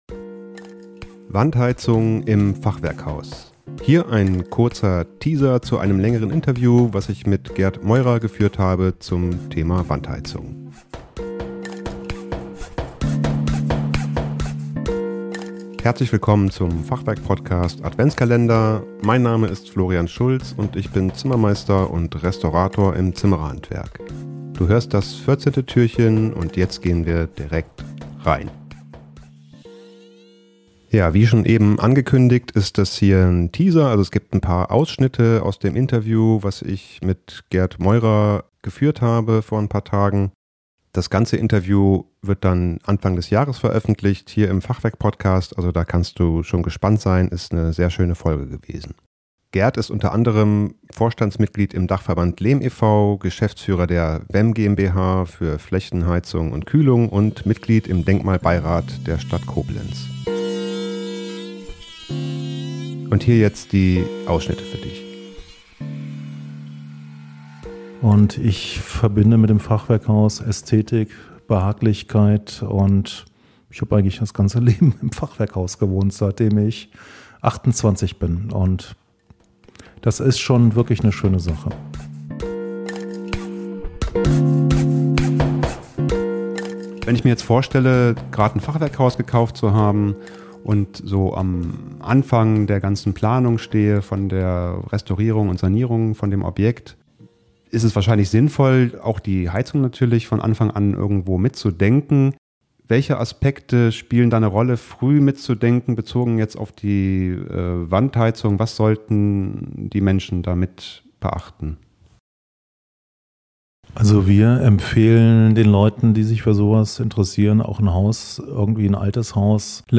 Wandheizung im Fachwerkhaus - Teaser zum Interview